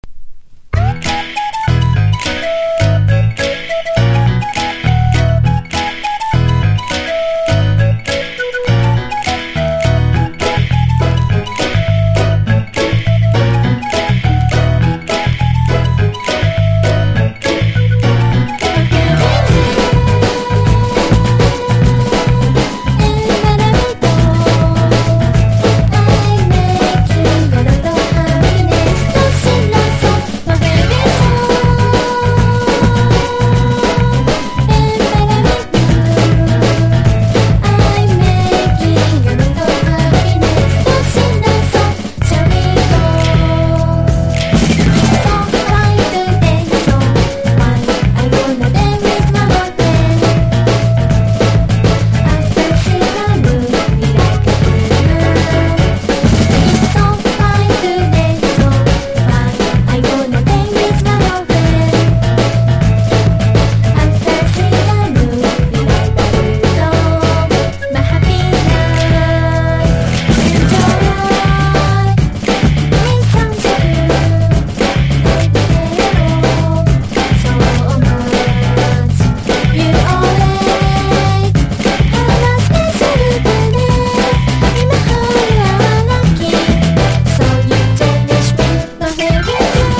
# POP